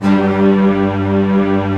Index of /90_sSampleCDs/Optical Media International - Sonic Images Library/SI1_Fast Strings/SI1_Fast Tutti